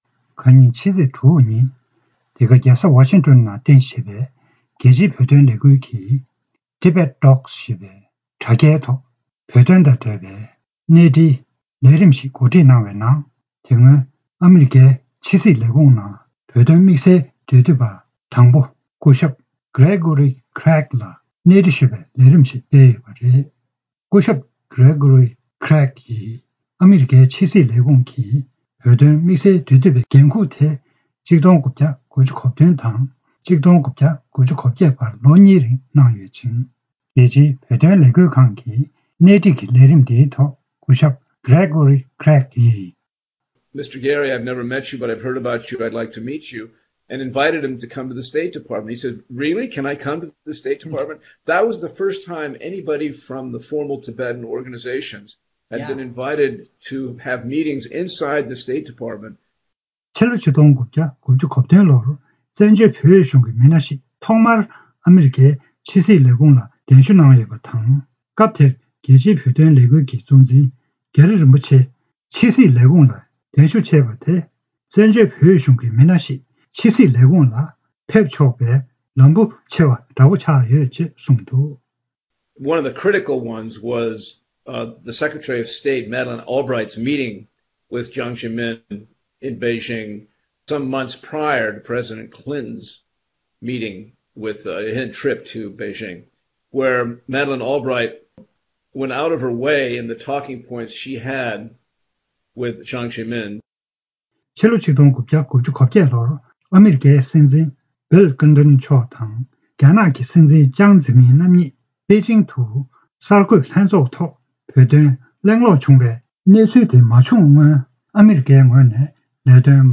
རྒྱལ་སྤྱིའི་བོད་དོན་ལས་འགུལ་ཁང་གིས་ཨ་རིའི་བོད་དོན་དམིགས་བསལ་འབྲེལ་མཐུད་པ་སྐུ་ཚབ་དང་པོ་དང་གླེང་མོལ་ཞུས་པ།
སྒྲ་ལྡན་གསར་འགྱུར། སྒྲ་ཕབ་ལེན།